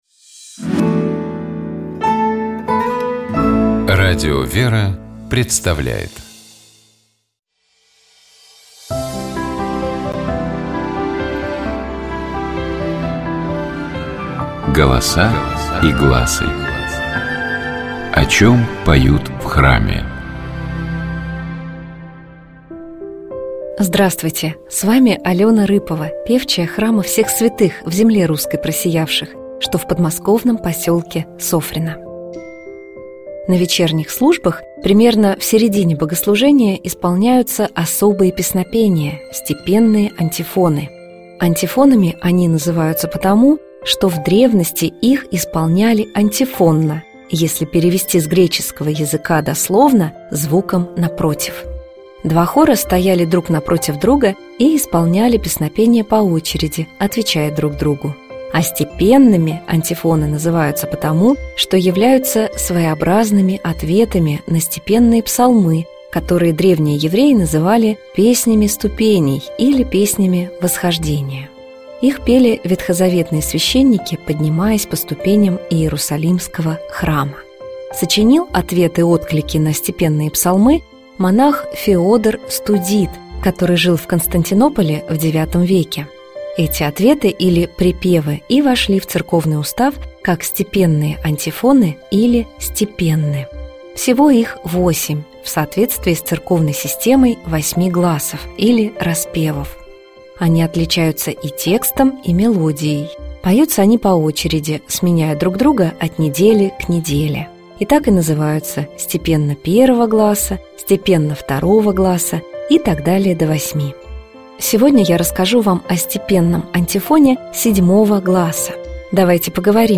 Golosa-i-glasy-Plen-Sion-ot-lesti-obrativ-stepennyj-antifon-7-go-glasa.mp3